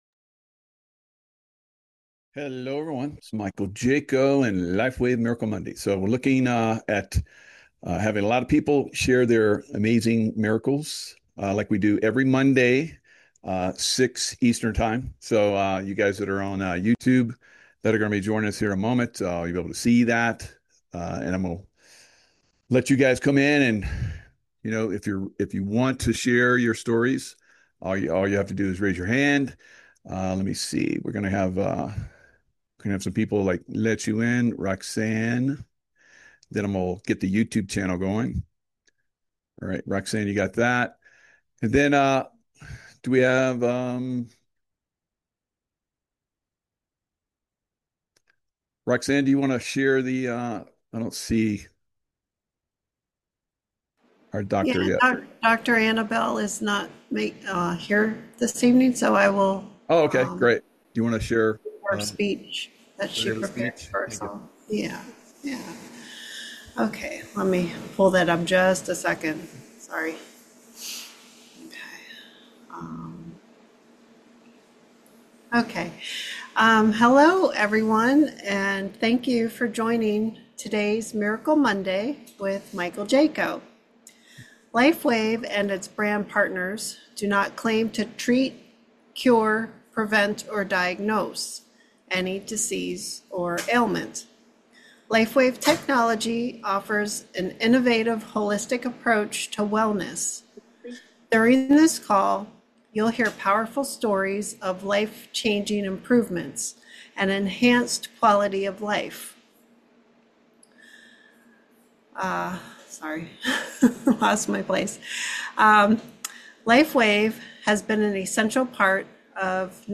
Every Monday, people gather to share their amazing stories about LifeWave's X39 patches. These patches use light to help our bodies heal and feel better. Users have experienced less pain, better sleep, and healthier skin.